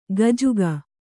♪ gajuga